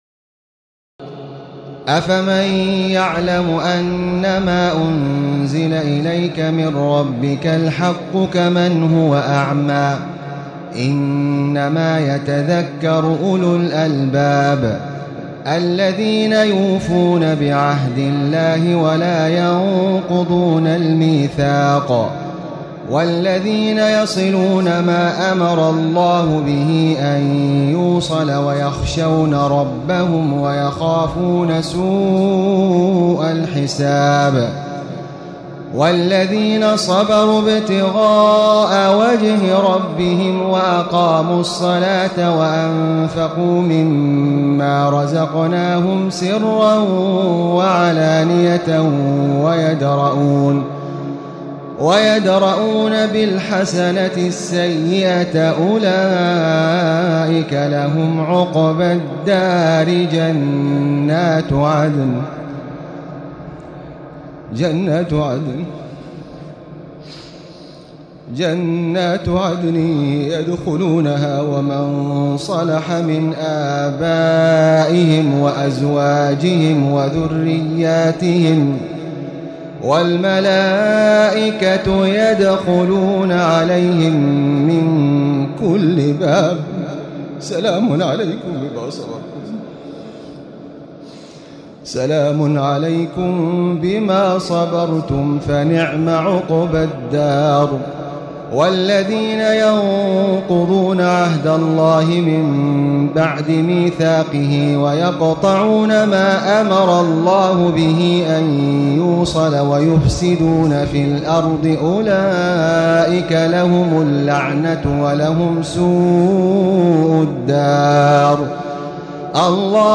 تراويح الليلة الثانية عشر رمضان 1436هـ من سورتي الرعد (19-43) و إبراهيم كاملة Taraweeh 12 st night Ramadan 1436H from Surah Ar-Ra'd and Ibrahim > تراويح الحرم المكي عام 1436 🕋 > التراويح - تلاوات الحرمين